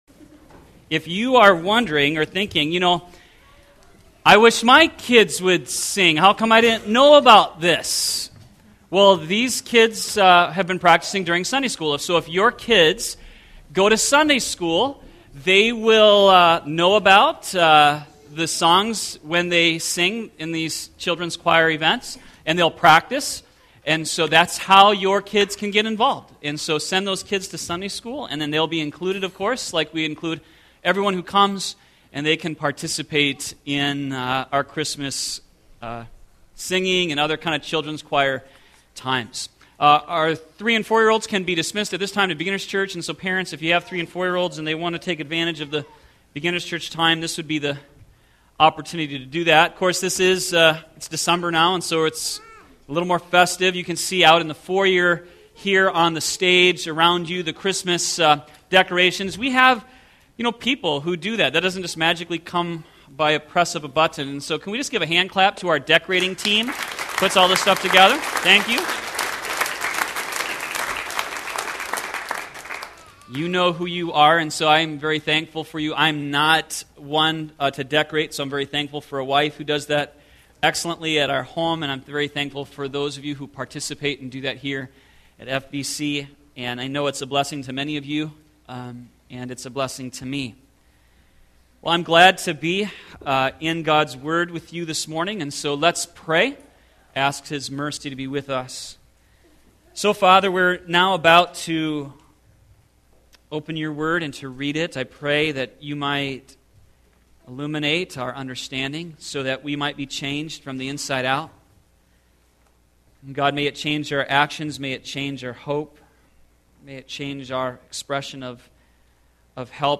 sermon12714.mp3